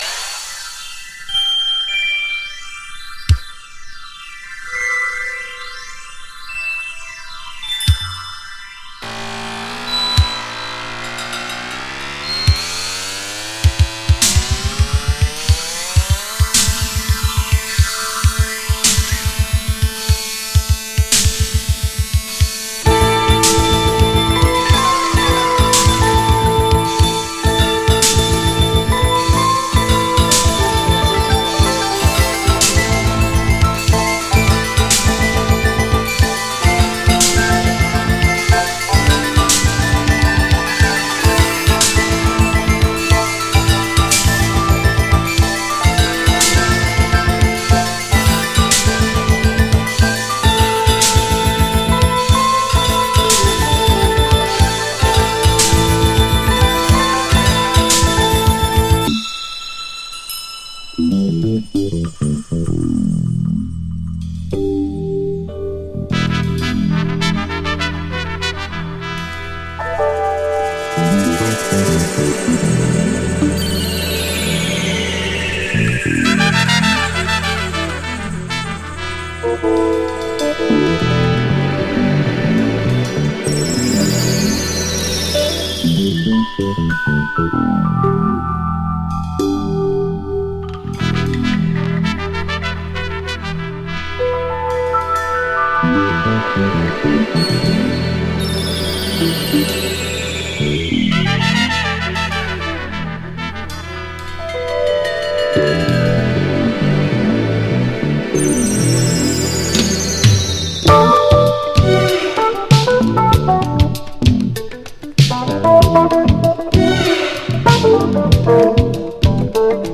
レアグルーヴィ